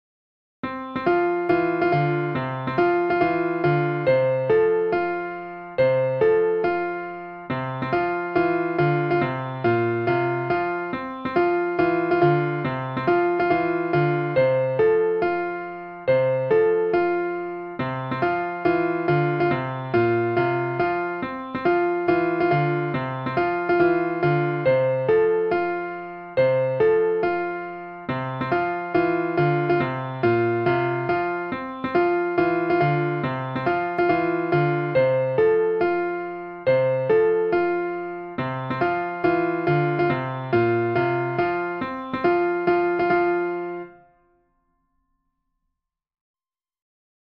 is a traditional nursery rhyme or counting song for children
for piano